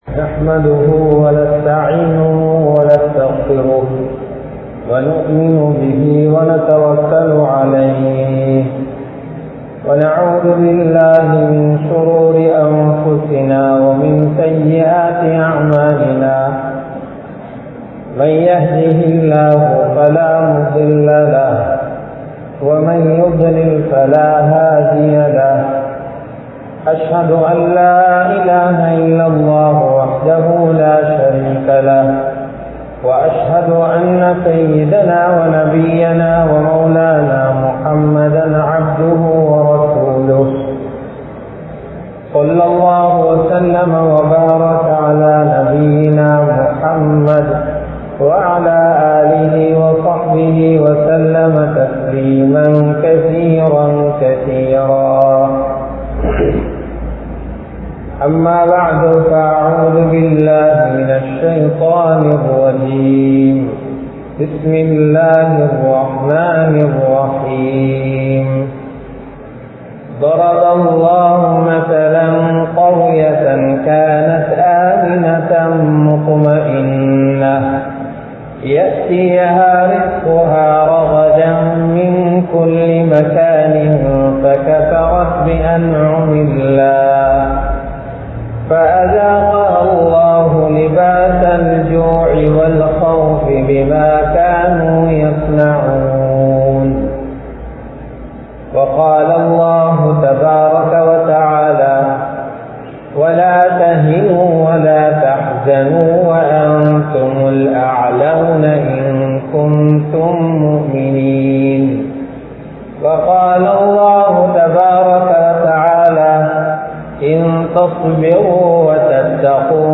Paavangalai Niruththungal (பாவங்களை நிறுத்துங்கள்) | Audio Bayans | All Ceylon Muslim Youth Community | Addalaichenai
Muhideen Jumua Masjith